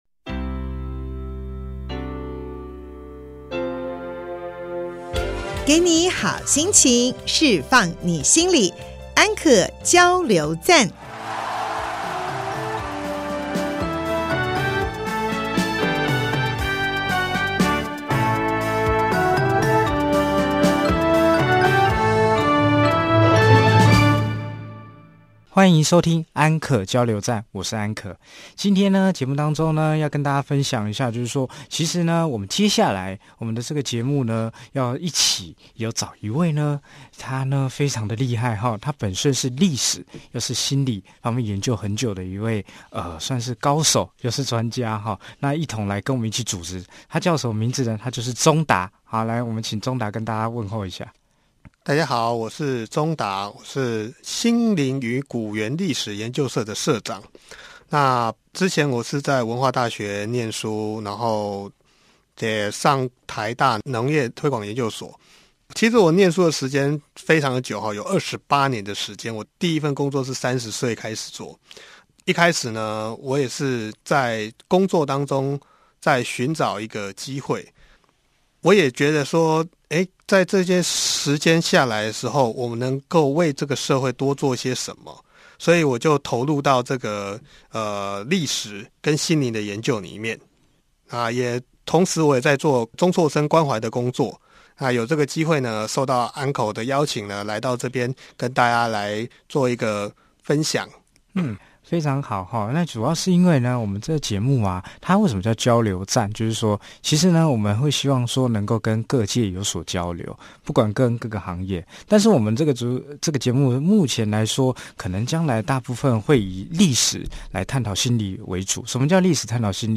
節目裡有生活點滴的分享、各界專業人物的心靈層面探析及人物專訪